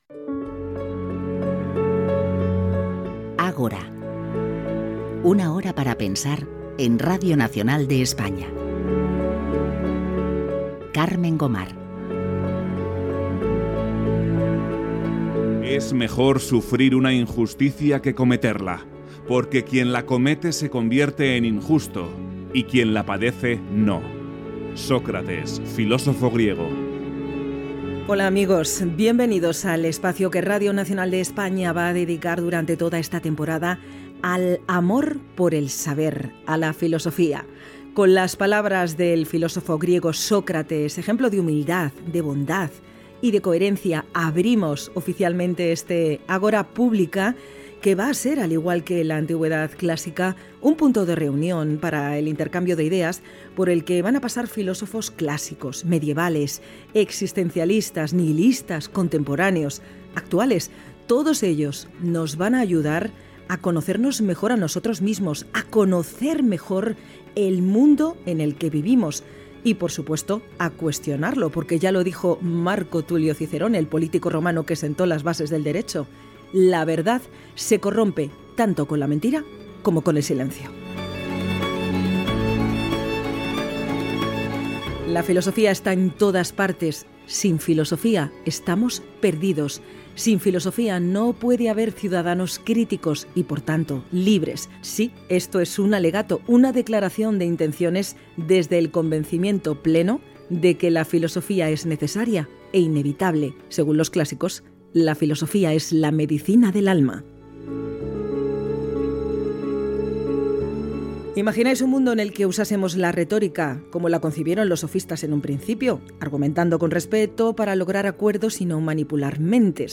Careta del programa, frase de Sòcrates, presentació del nou programa dedicat a la filosofia, sumari